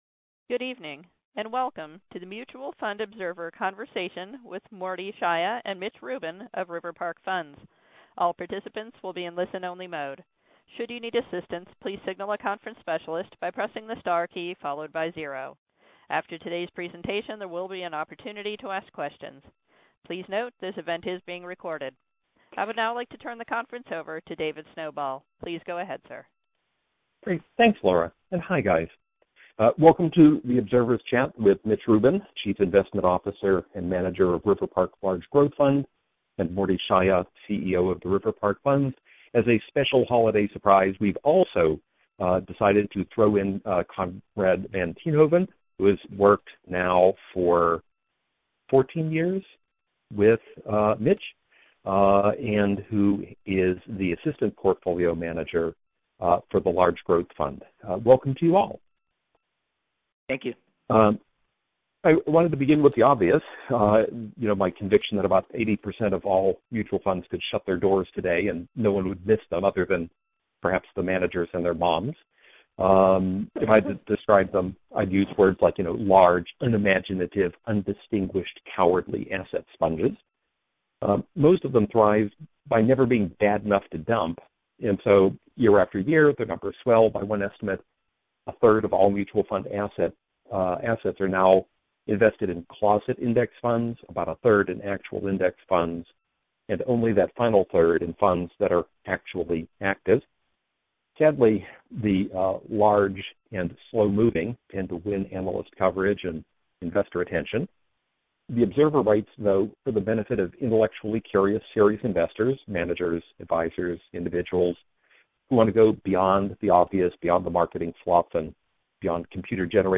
Here’s what we heard on the call.